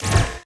SCIMisc_Sci Fi Shotgun Reload_04_SFRMS_SCIWPNS.wav